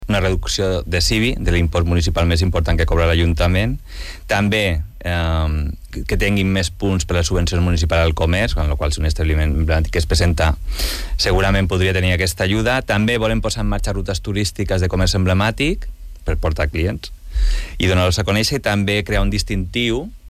Són declaracions a l’Informatiu Vespre d’IB3 Ràdio.